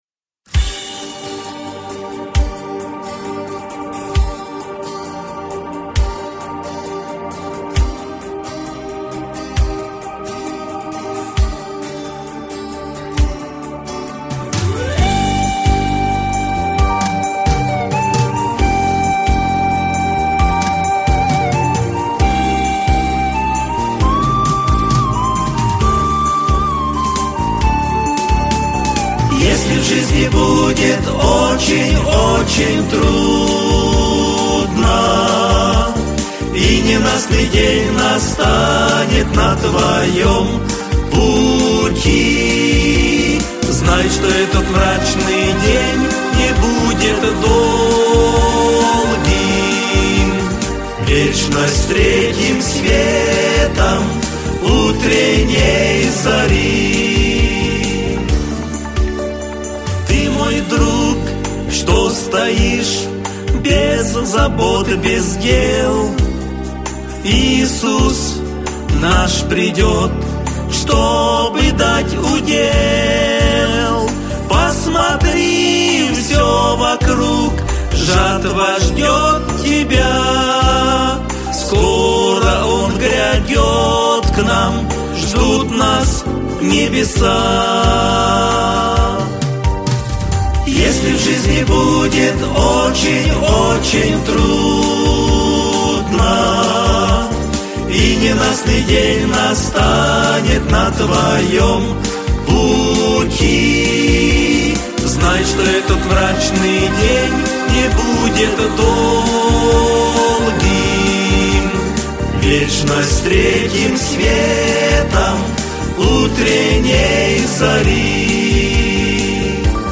164 просмотра 656 прослушиваний 25 скачиваний BPM: 132